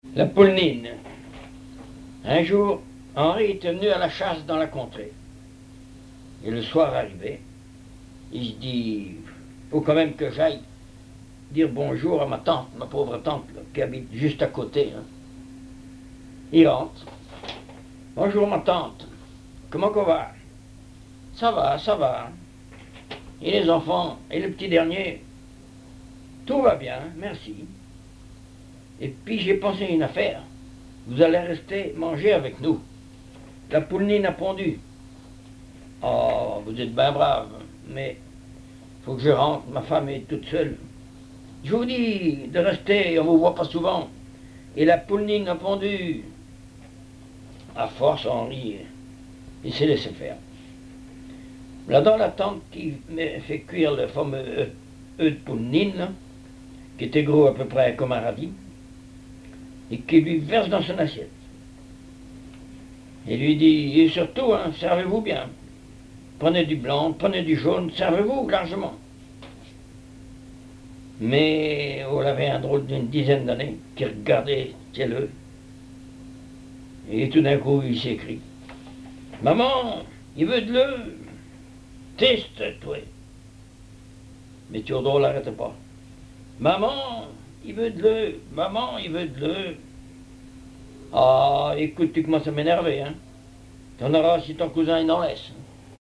Genre conte
Enquête Arexcpo en Vendée-Association Joyeux Vendéens
Catégorie Récit